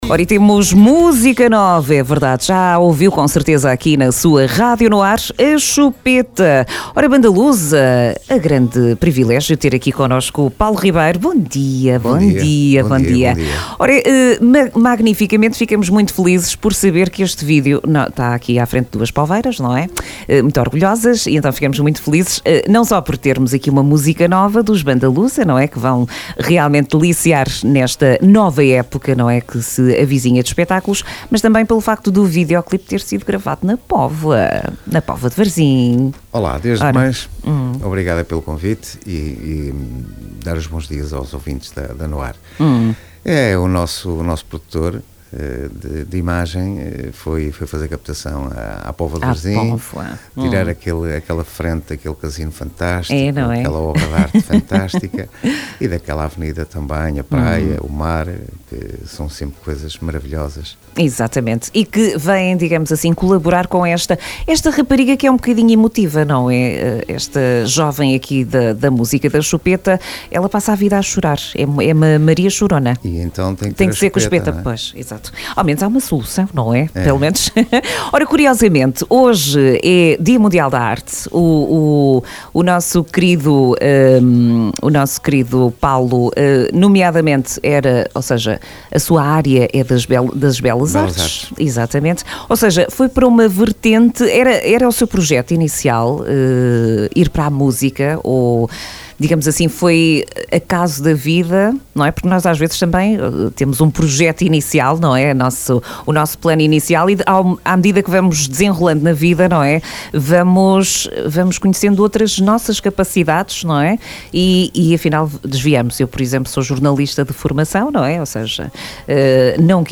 Entrevista Bandalusa nas manhã NoAr dia 15 de Abril 2025
ENTREVISTA-BANDALUSA.mp3